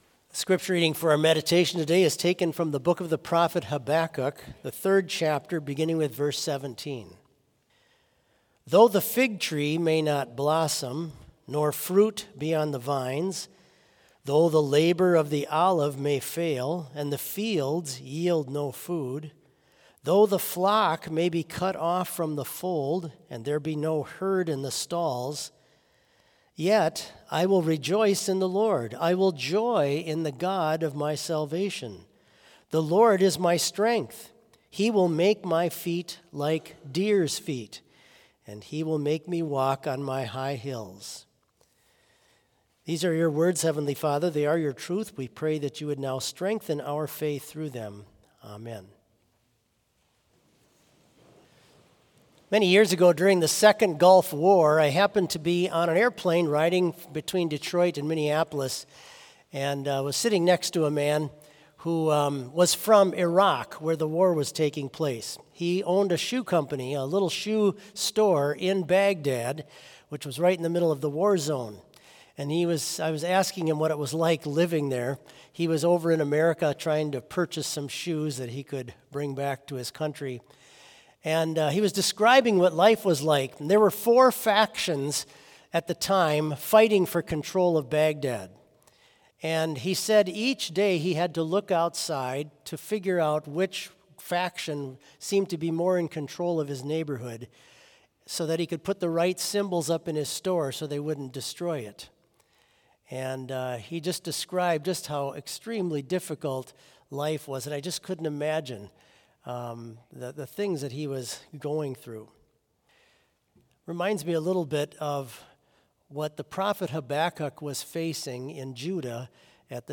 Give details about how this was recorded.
Spoken responsively